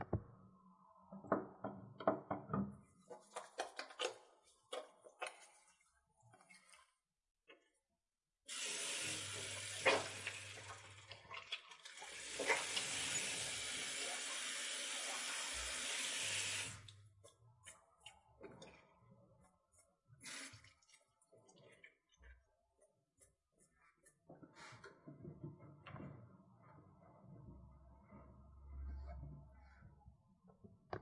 UEM 声音 " 洗手 (midplane)
描述：有人在一间小浴室洗手的声音。此声音已使用变焦记录器录制，并且已经过修饰，消除了背景噪音。声音记录在一米的距离。
标签： 肥皂 洗涤 浴室
声道立体声